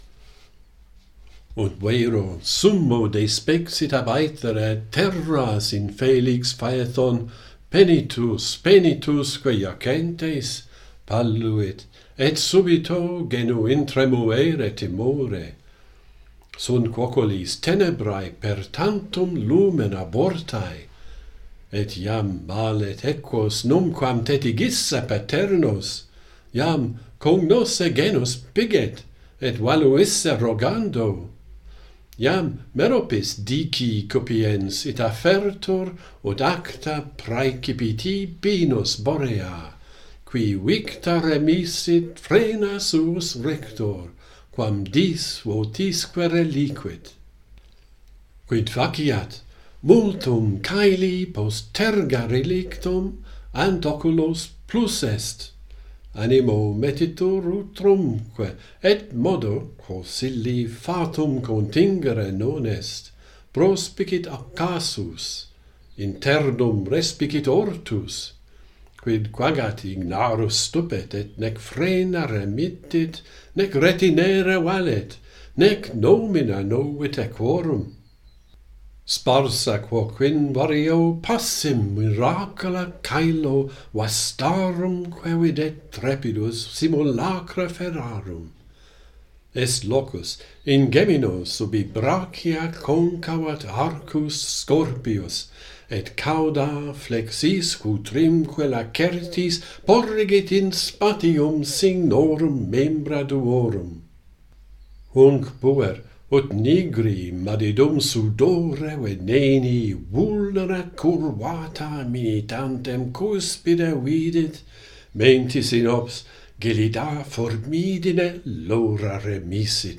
Phaethon, continued - Pantheon Poets | Latin Poetry Recited and Translated